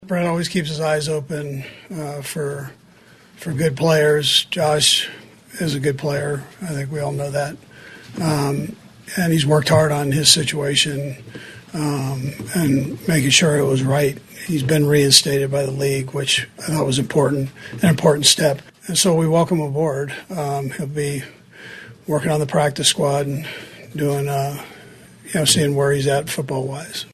Kansas City Chiefs Coach Andy Reid met with the media Wednesday.